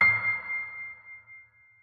piano-sounds-dev
c6.mp3